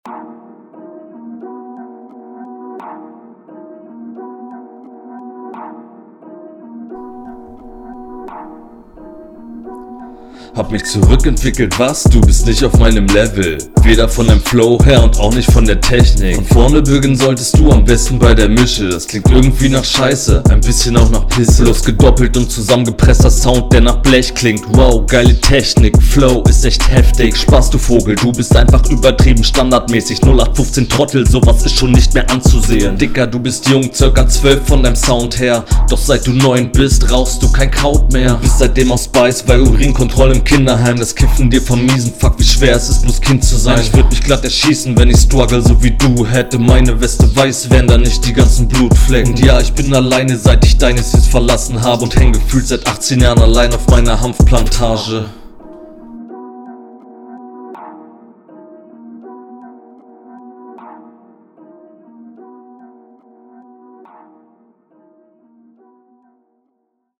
Bisschen monoton auf Dauer das kenne ich besser von dir diggi, flow wie immer relativ …
brudi n bissl komisch mischen zu dissen, wenn man nach raw und egh mic klingt. …